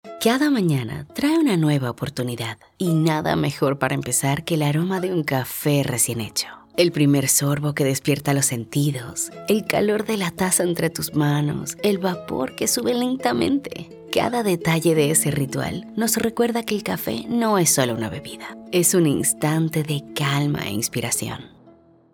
Female
Spanish (Latin American), English (Latin American Accent)
Warm & Gentle Narration
0209narracion_cafe.mp3